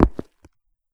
footstep5.wav